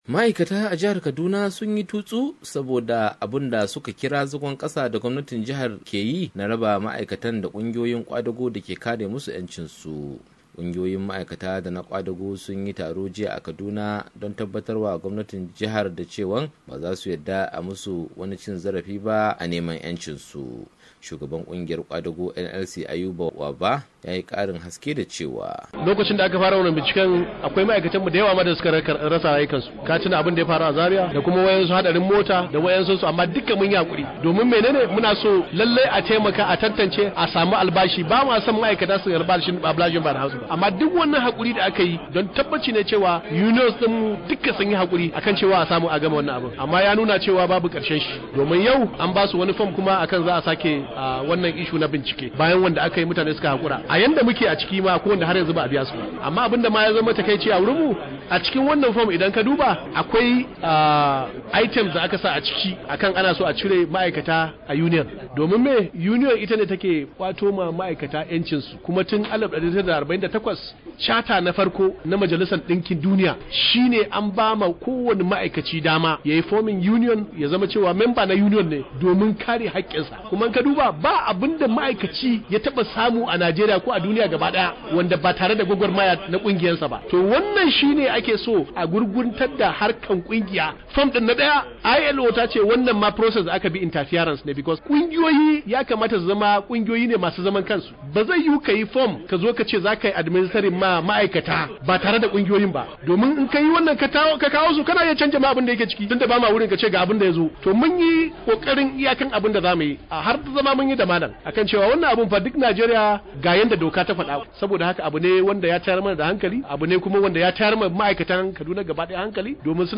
Ma'aikata a jahar Kaduna sun ce suna tare da kungiyar kwadago ta kasa daram, babu wanda zai iya raba su da ita, kamar yadda za a ji a cikin wannan rahoto.